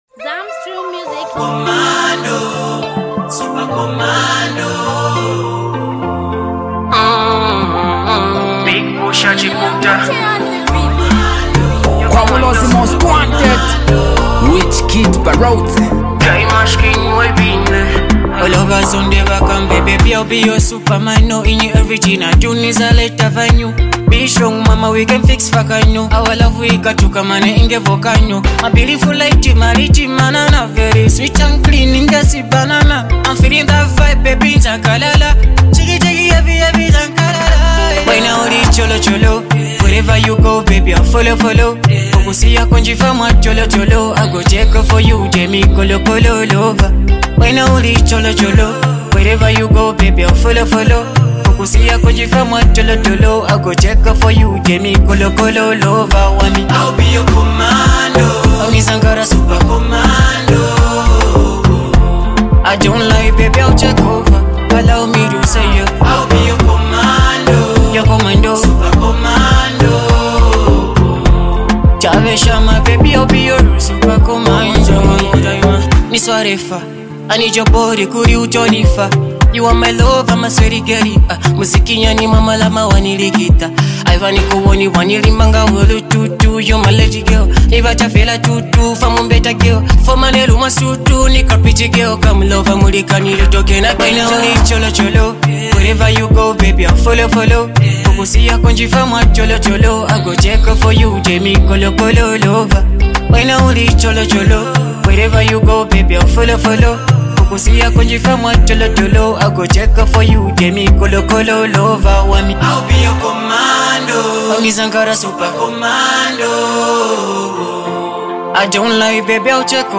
vibrant masterpiece sound